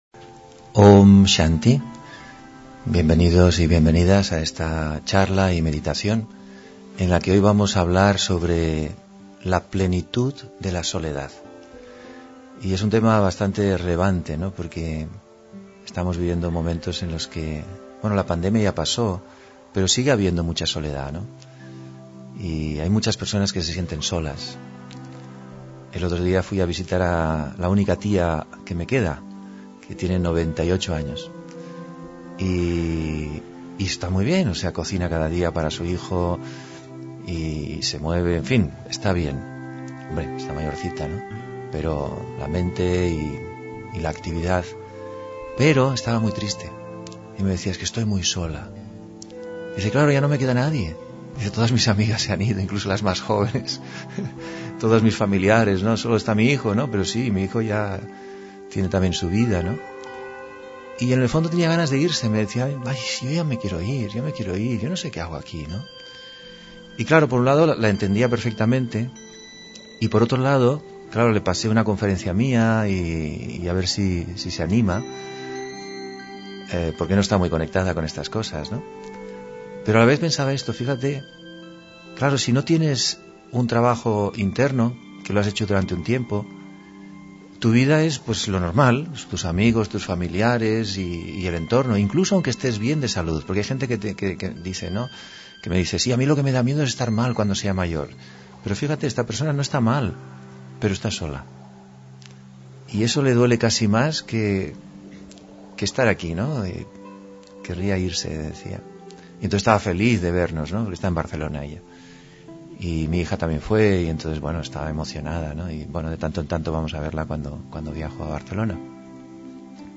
Meditación y conferencia: La plenitud de la soledad (3 Febrero 2023)